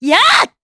Xerah-Vox_Attack2_Madness_jp.wav